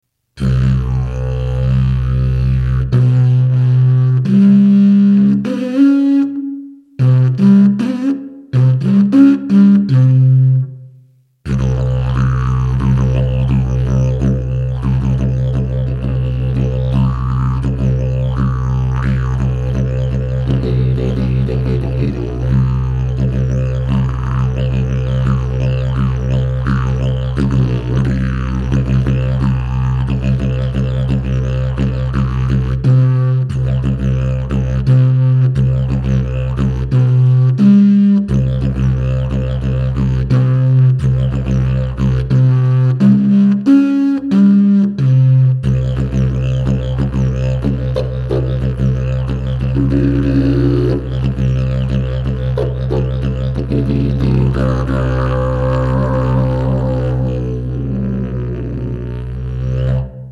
drumparam: didgeridoo, hempstone, zelfo, hanfdidgeridoo, hempdidg, hempdidgeridoo
Dieses eigenwillige und weltweit einmalige Didgeridoo ist in C / C / G. Es ist ein experimenteller Prototyp in neuer Verbundtechnik.
This and the so formed strong stiffness of the instrument allowes high frequent oscillations with minimised attenuation.
Dg428 Basic and 6 overblows and the ranges to pull the sound by variating tension of lip